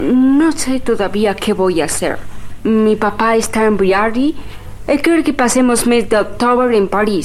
Fragment de l'adaptació de l'obra "Dos mujeres a las 9" de Juan Ignacio Luca de Tena
Ficció